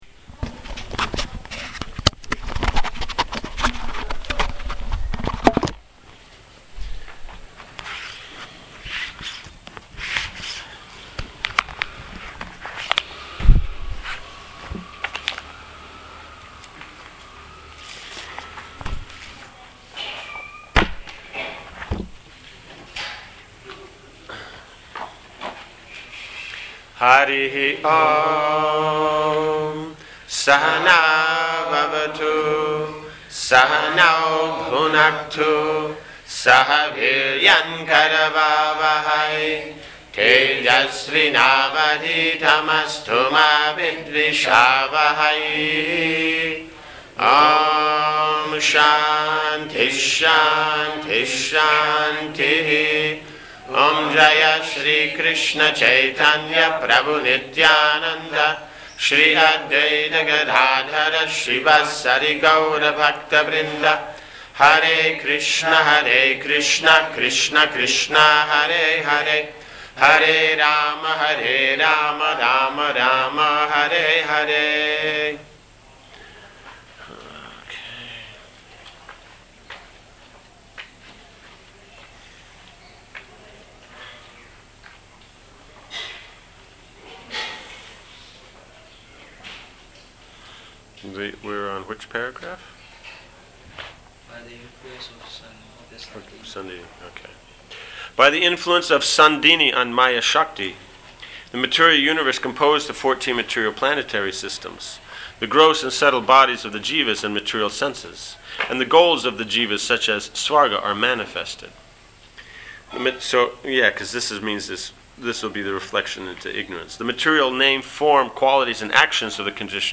Transcription of lecture #7 of Śrī Śrī Caitaya Śikṣāmṛta and Daśa Mūla Tattva course, given in Bhaktivedānta Academy, Śrī Māyāpura